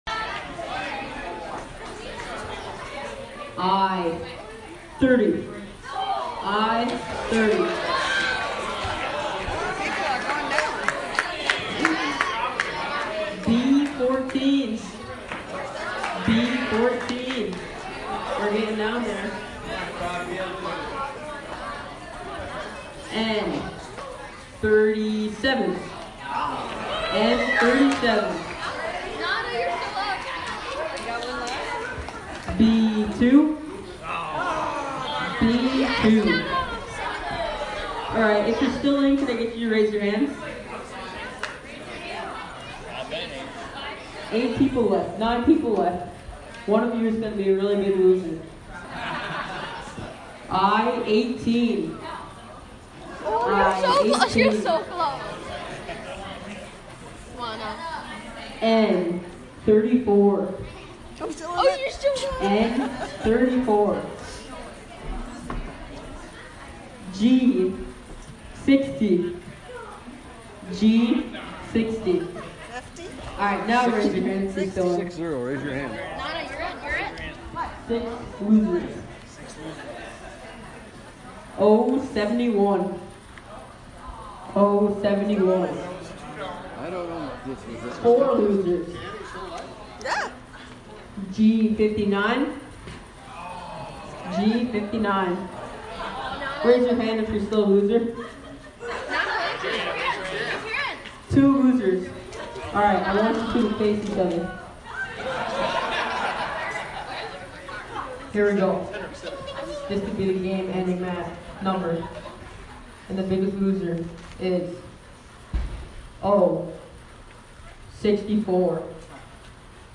Download Free Bingo Sound Effects
Download Bingo sound effect for free.